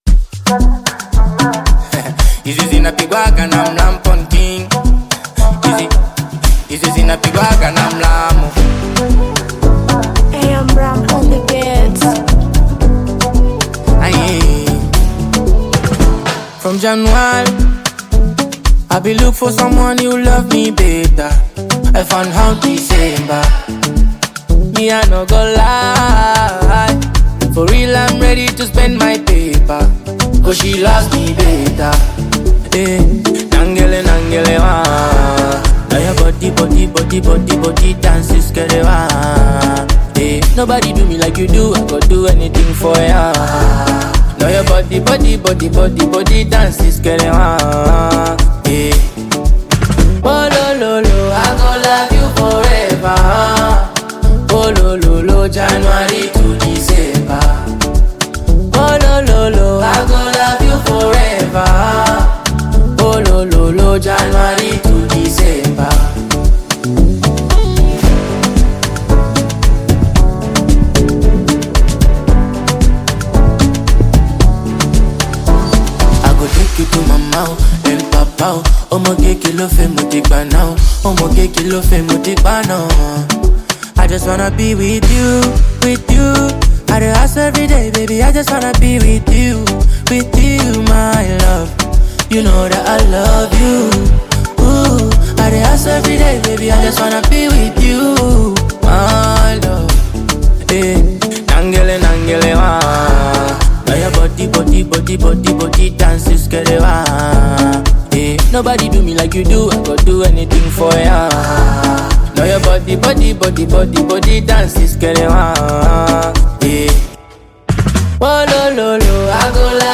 Bongo Flava music track